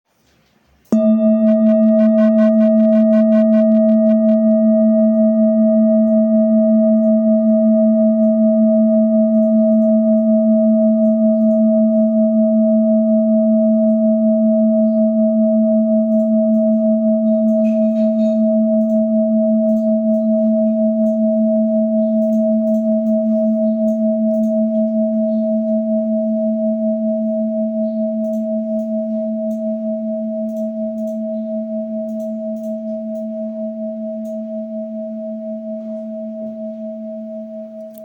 Full Moon Bowl, Buddhist Hand Beaten, Moon Carved, Antique Finishing
Material Seven Bronze Metal
This is a Himalayas handmade full moon singing bowl.